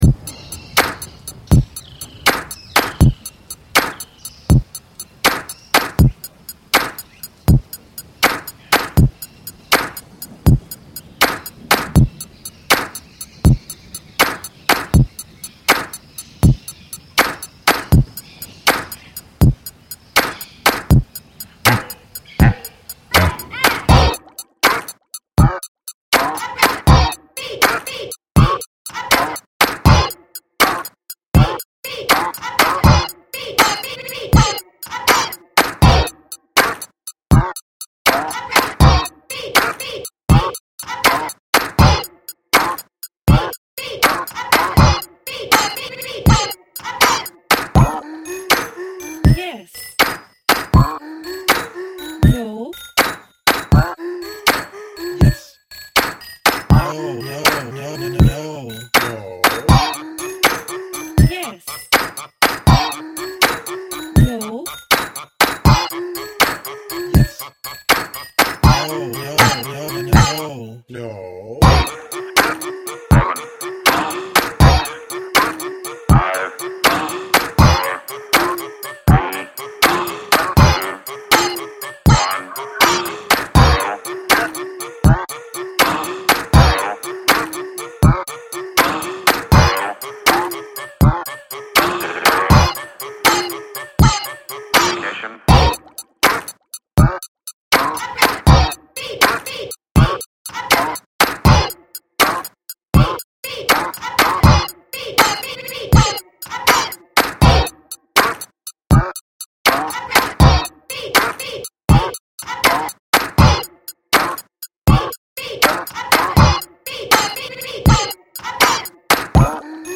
Judgement Rationale: This artist created a good groove with edge.
Creative use of telephone as a melody in last part of piece.